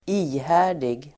Uttal: [²'i:hä:r_dig]